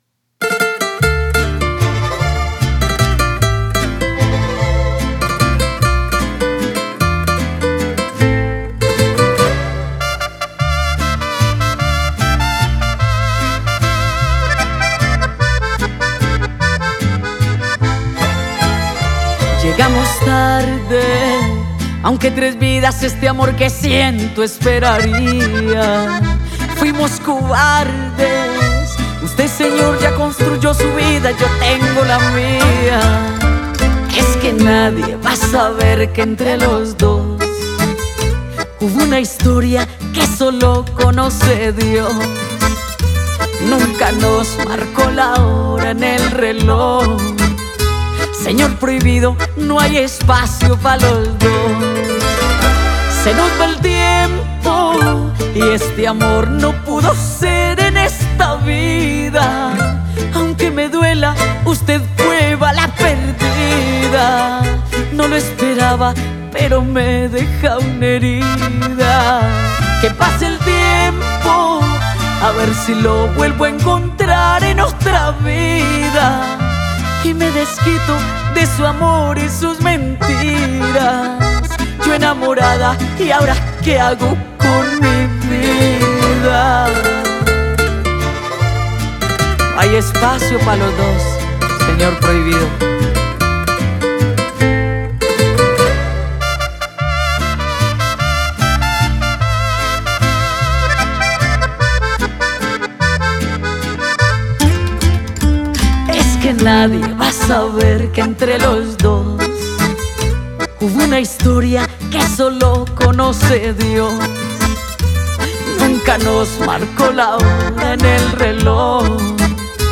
La Reina de la música popular